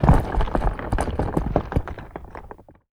rock_smashable_falling_debris_02.wav